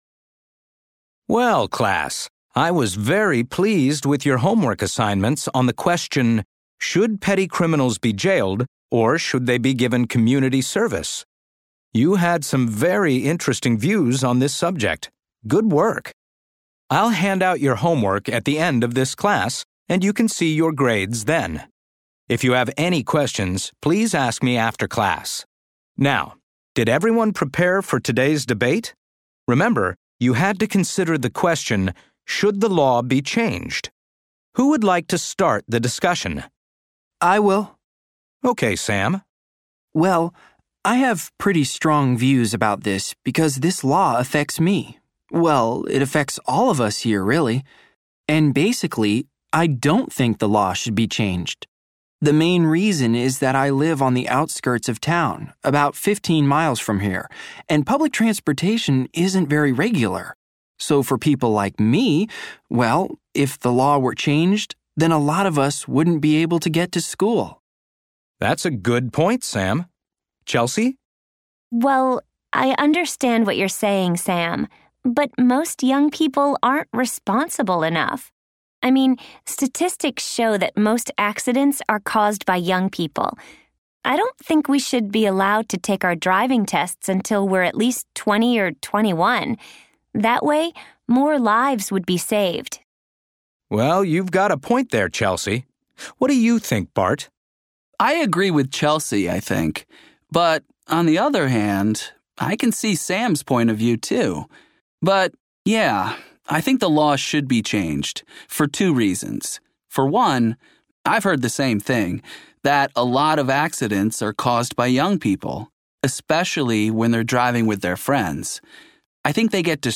Class debate about petty crimes:
class-debate-navigators-listening.mp3